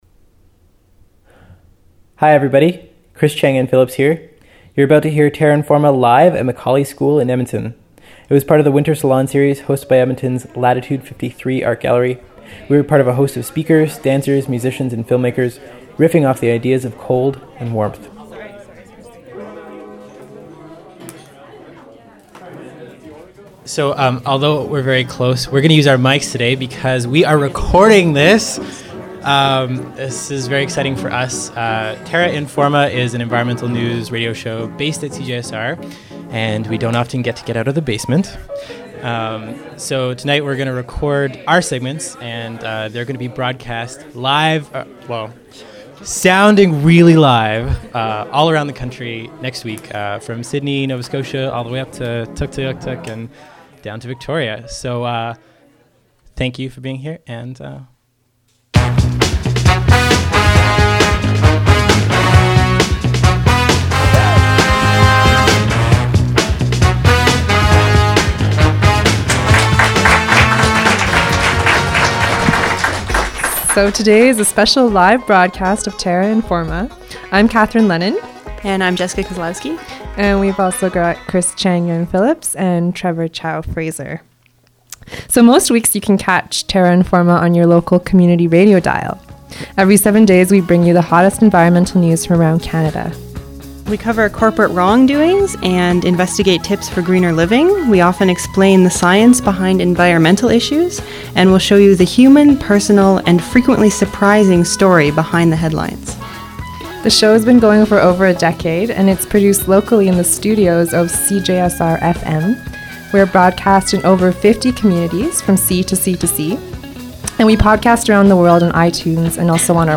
Live show about winter cities, how temperature works, and the most magic temperature.